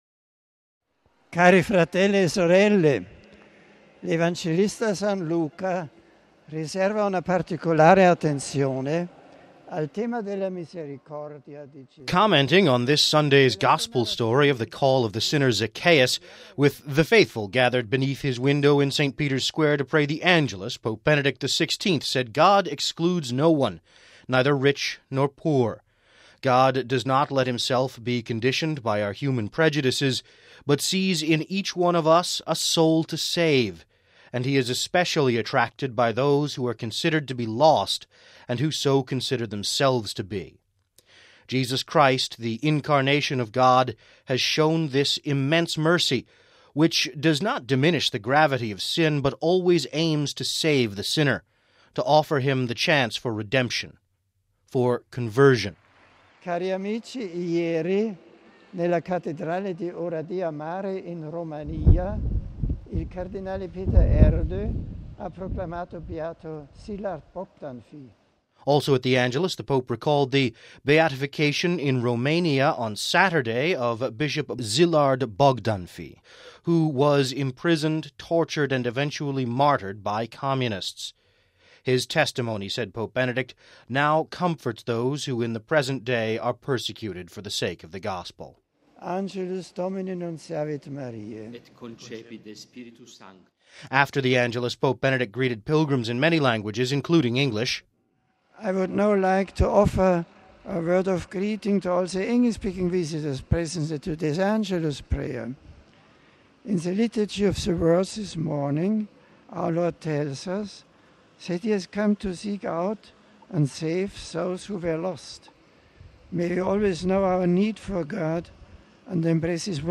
Commenting on this Sunday’s Gospel story of the call of the sinner, Zacchaeus, with the faithful gathered beneath his window in St Peter’s Square to pray the Angelus, Pope Benedict XVI said God excludes no one, neither rich nor poor, Zacchaeus.
After the Angelus, Pope Benedict greeted pilgrims in many languages, including English.